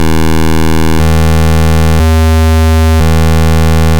PWM waveforms
The classic NES APU waveforms include 4 different pulse widths – 12.5% (1⁄8), 25% (1⁄4), 50% (1⁄2), 75% (3⁄4) – though 2 of them (25% and 75%) sound the same.
The following example plays the four variations in order, using an 80 Hz base frequency.
pd-pwm.mp3